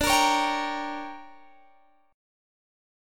Listen to DM7sus4#5 strummed